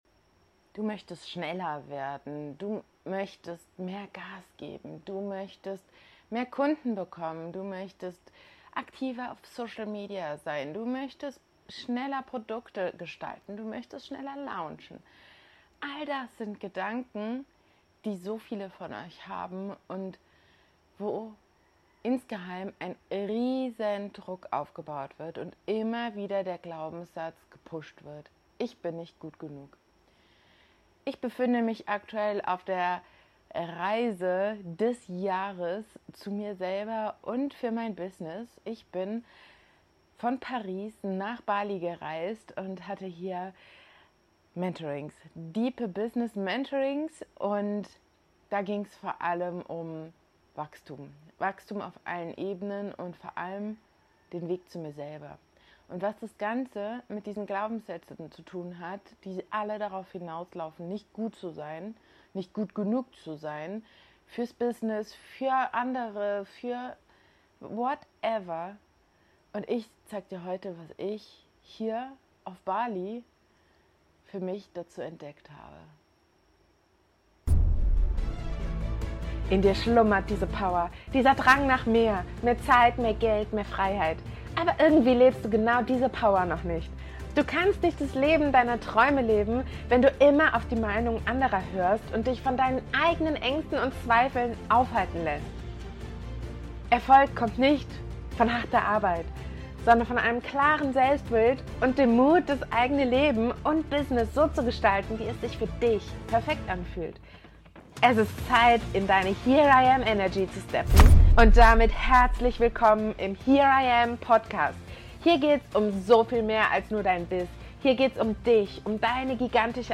Eine weitere Folge live aus Bali.